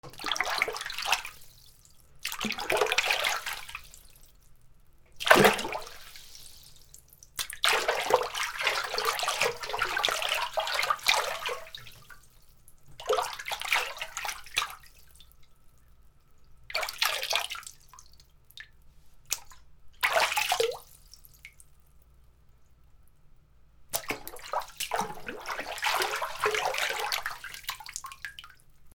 水音 水の中で手を動かす
『パシャパシャ』